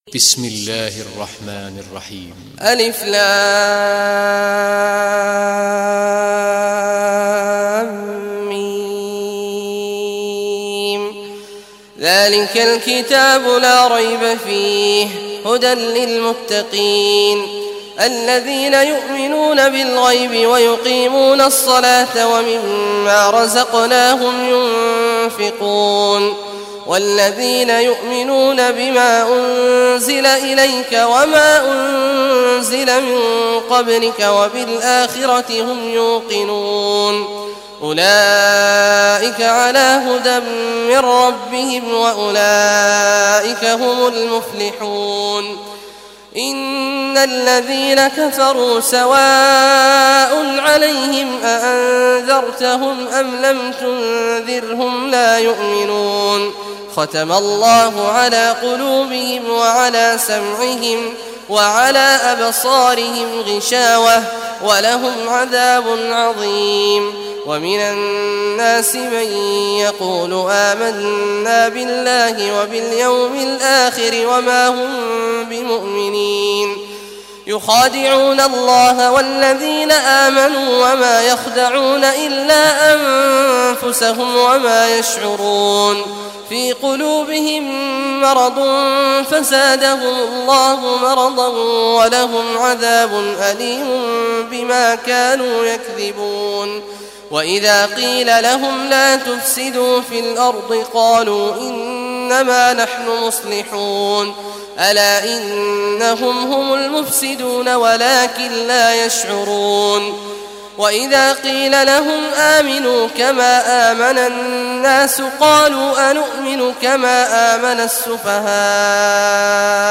Surah Baqarah Recitation by Sheikh Awad al Juhany
Surah Baqarah, listen or play online mp3 tilawat / recitation in Arabic in the beautiful voice of Sheikh Abdullah Awad Al Juhany.